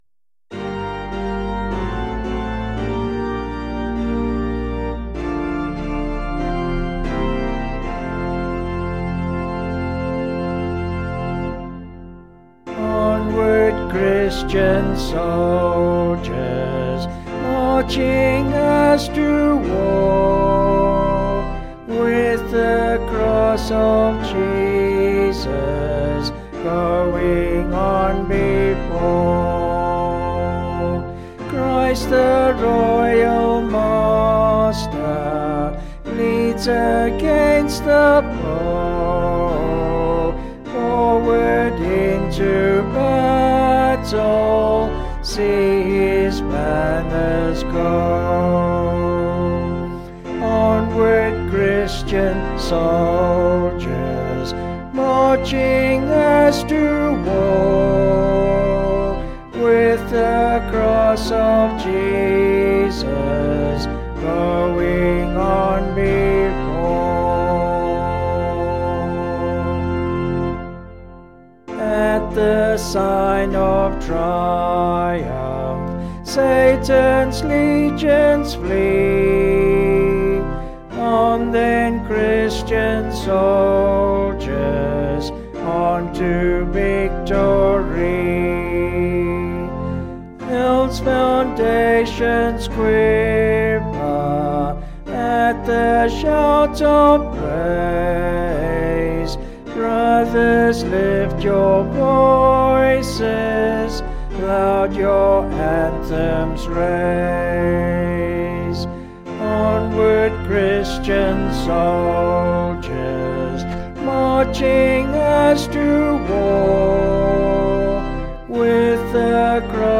Vocals and Organ 267.9kb Sung Lyrics